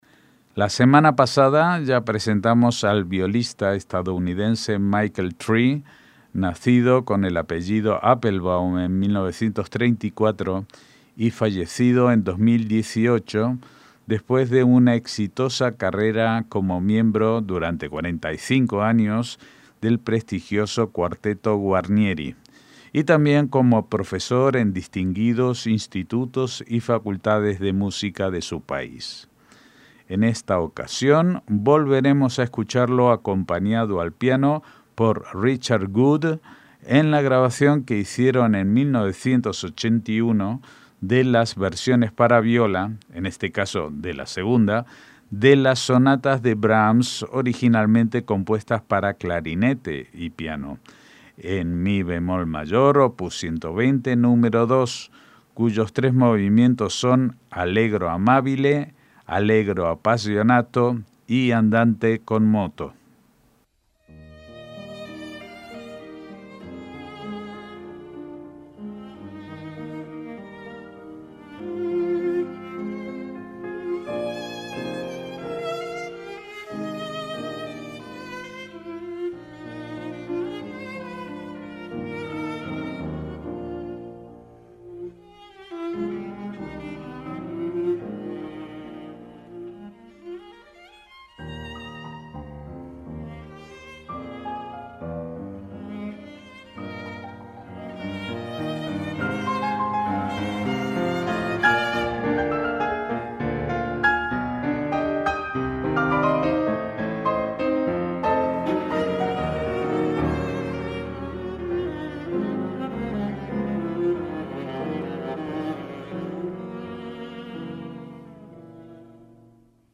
MÚSICA CLÁSICA
piano
en mi bemol mayor